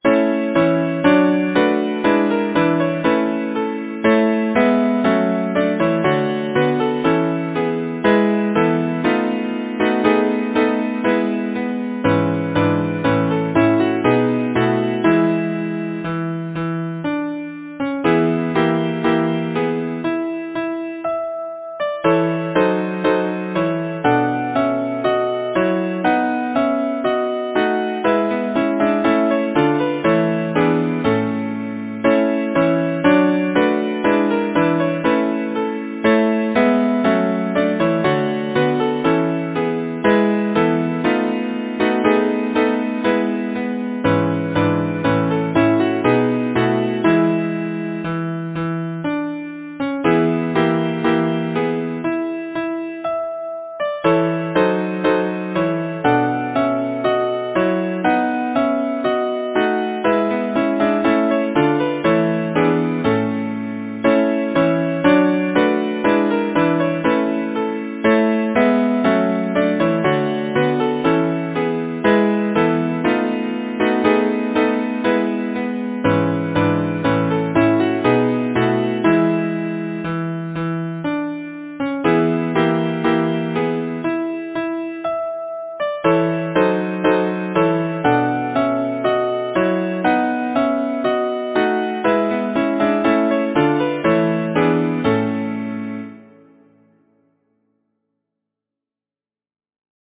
Title: Tell me, roses Composer: Joseph Barnby Lyricist: Anonymous lyricist Number of voices: 4vv Voicing: SATB Genre: Secular, Partsong
Language: English Instruments: A cappella